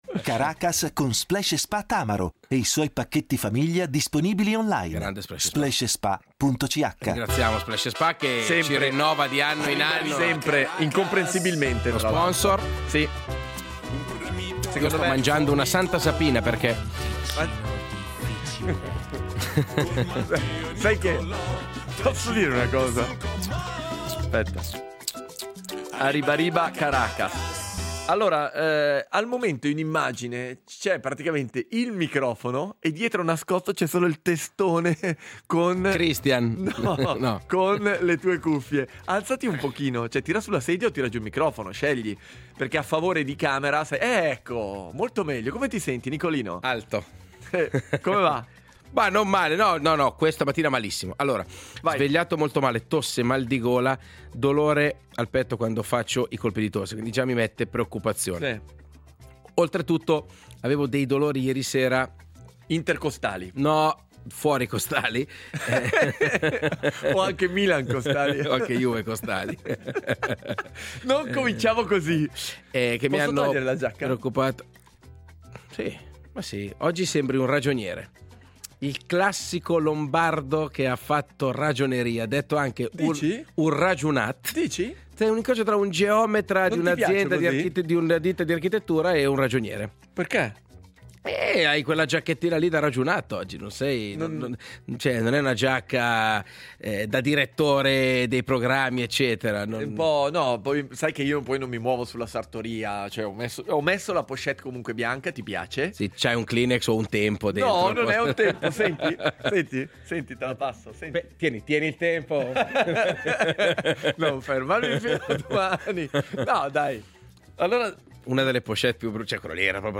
All’elenco di porta fortuna, talismani, Santini, farmaci con prescrizione, senza prescrizione, con continuativo e senza continuativo (ovviamente tutti menzionati più volte nonostante l’UFCOM) si aggiungono quelle caramelle balsamiche per la tosse a base di gemme di abete rosso (ve ne sarete forse accorti all’ascolto con quel piacevole sgranocchiare che ci tiene compagnia per tutta la puntata).